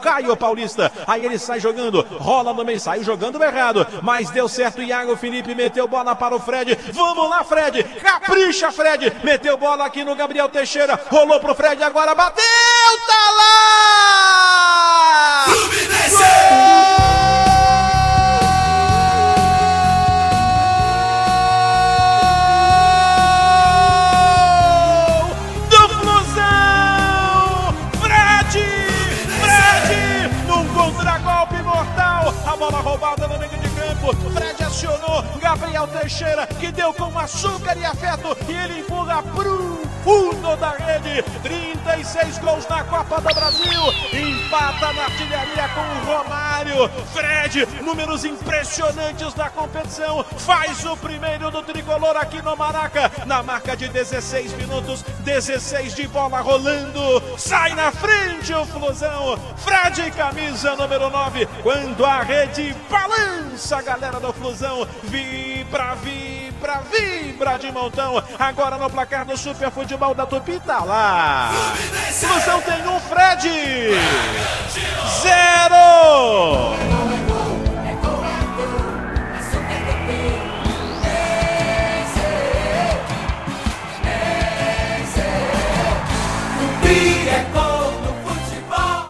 Ouça os gols da vitória do Fluminense sobre o Bragantino com a narração